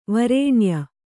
♪ varēṇya